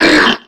Cri de Parecool dans Pokémon X et Y.